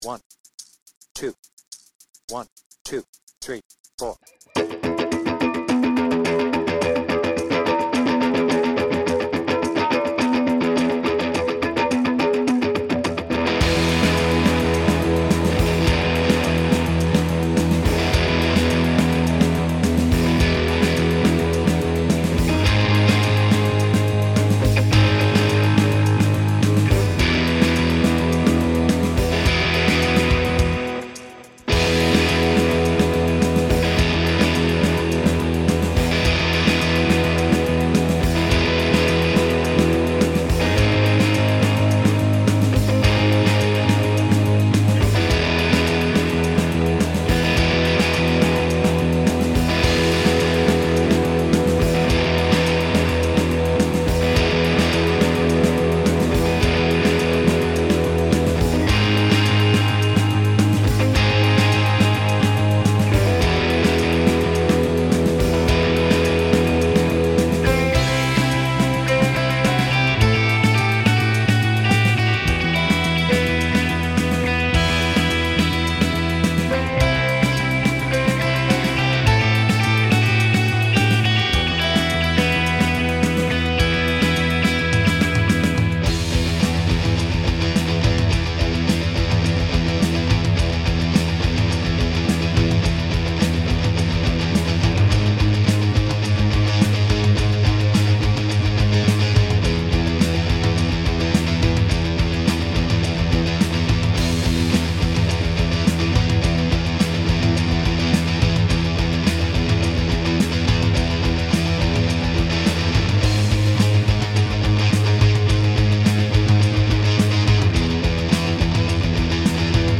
BPM : 106
Tuning : E
Based on 360° Tour and album